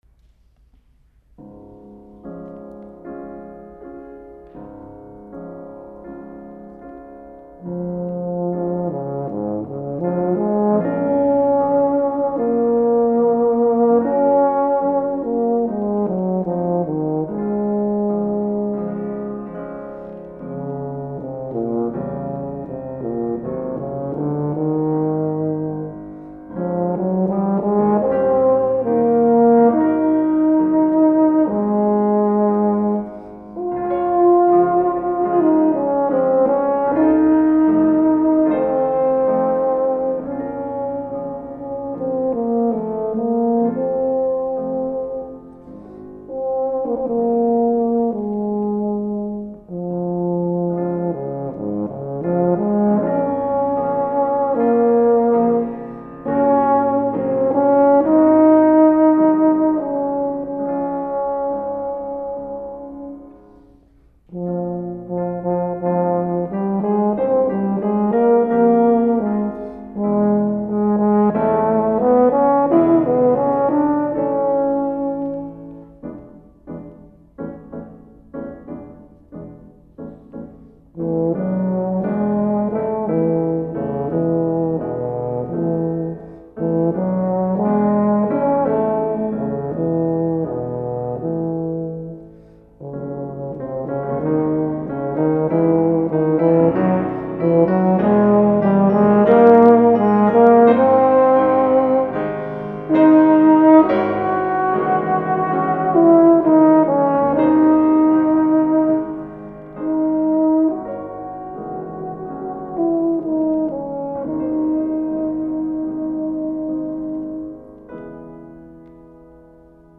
For Euphonium Solo
with Piano.